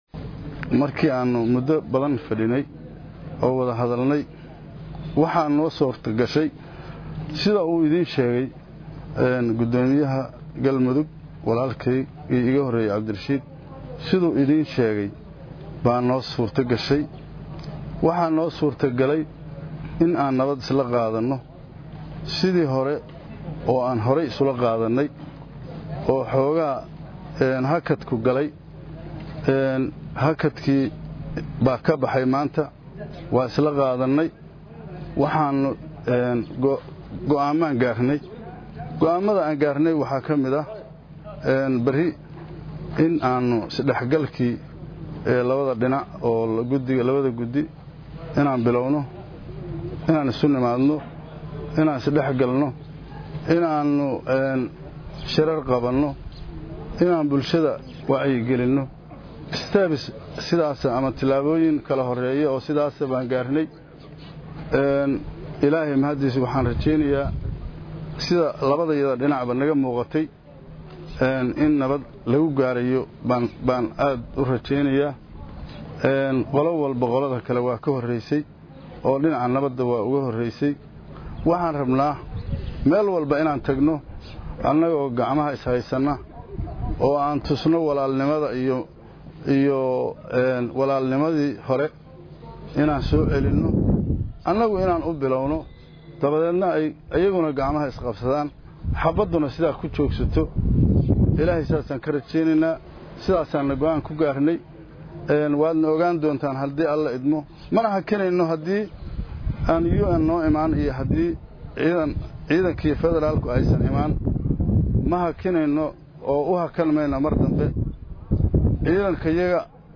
Gudoomiyaha Gobolka Mudug Xasan maxamed Nuur (Abgaaloow) oo saxaafadda la hadalaayey ayaa sheegay in ay doonayaan in ay cagaha udhigaan nabadda magaalada, sidoo kalana ay xaqiijindoonaan isku dhafkii gudiga labada dhinac, si loo xaqiijiyo goobihii ay ciidamadu kala joogeen.
Gudoomiyaha gobolka Mudug Puntland.